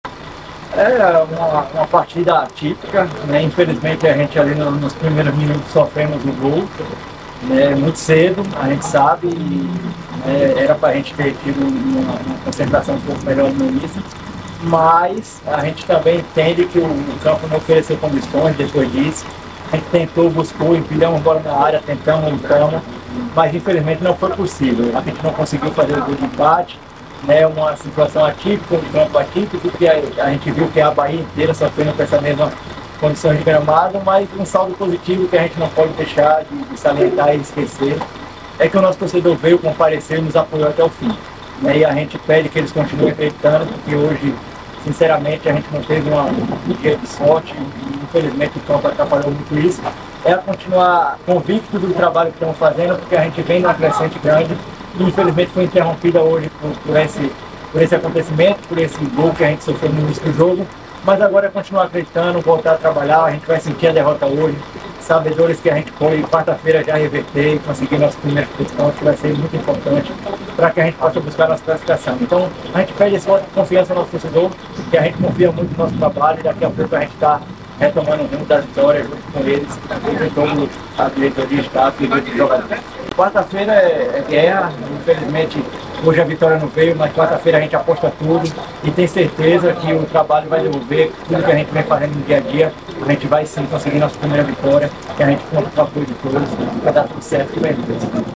Sonoras Pós Jogo, Jequié x Juazeirense